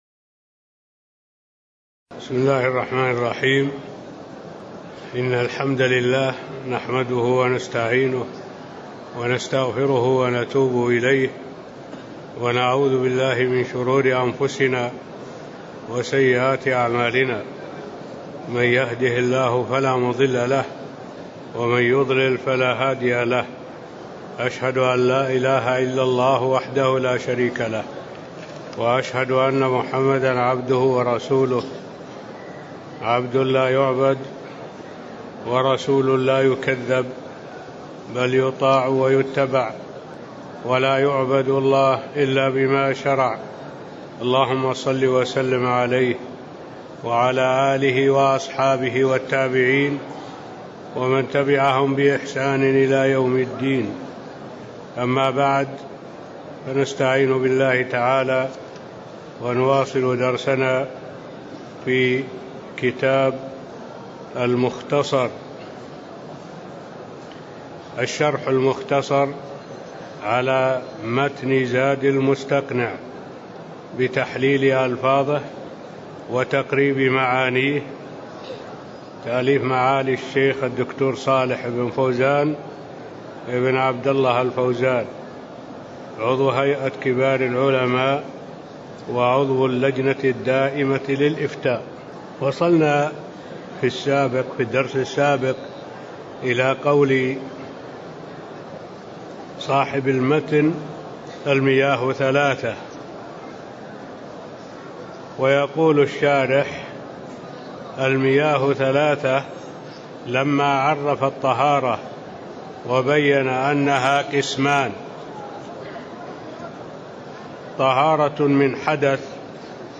تاريخ النشر ١٥ ربيع الأول ١٤٣٤ هـ المكان: المسجد النبوي الشيخ: معالي الشيخ الدكتور صالح بن عبد الله العبود معالي الشيخ الدكتور صالح بن عبد الله العبود من قوله: والمياة ثلاثة (02)